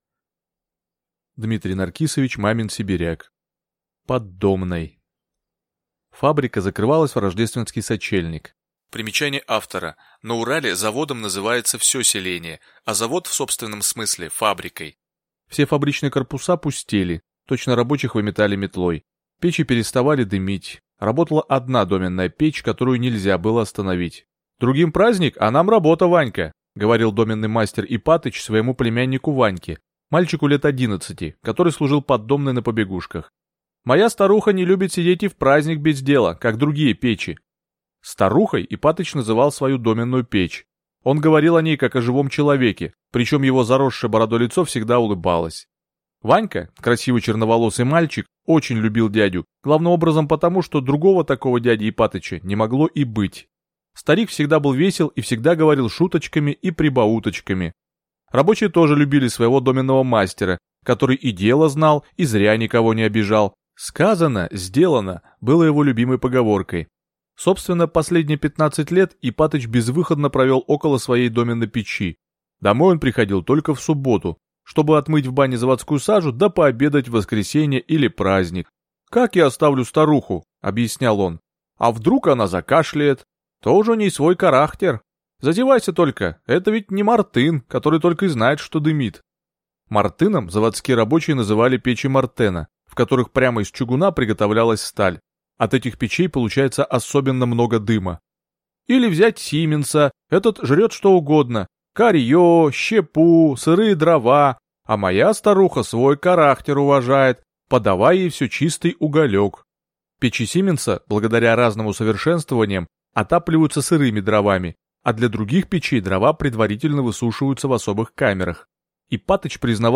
Аудиокнига Под домной